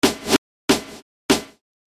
The easiest way to fade out our new fake tail is Edison’s ‘Fade out’ button.